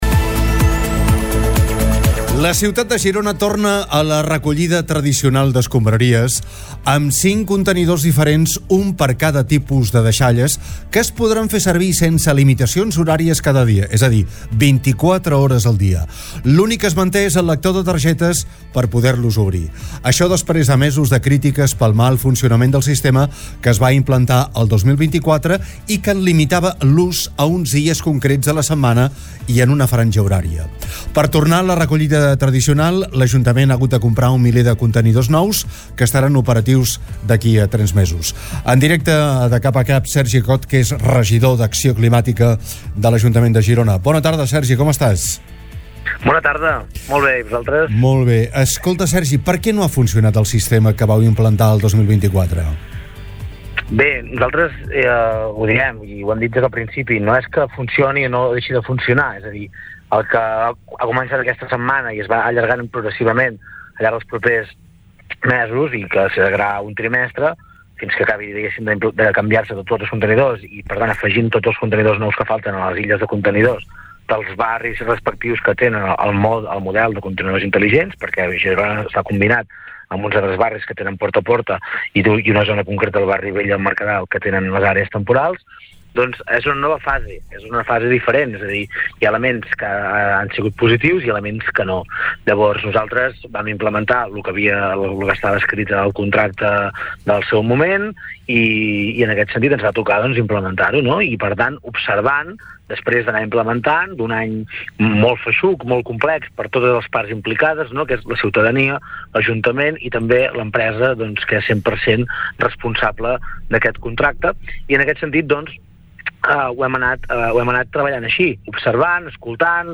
Sergi Cot, regidor d’Acció Climàtica, ha estat entrevistat